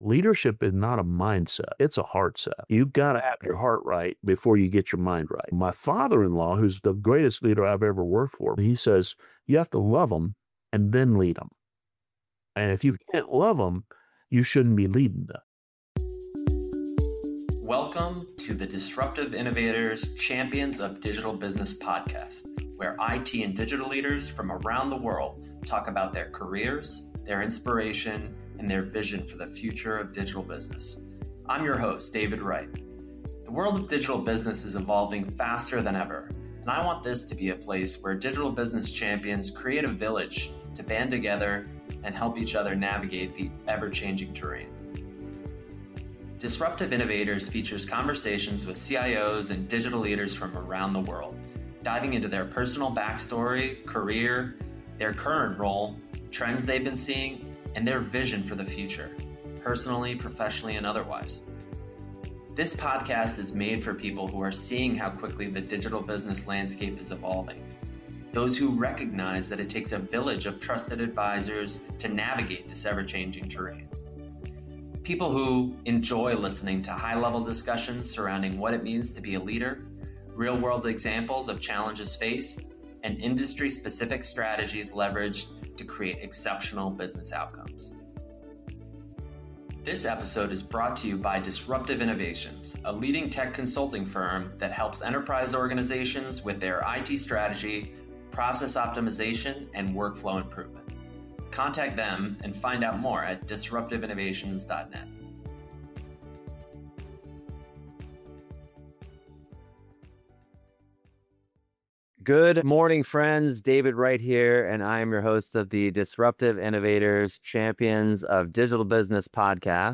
Disruptive Innovators: Champions of Digital Business - CIO & IT Leader Interviews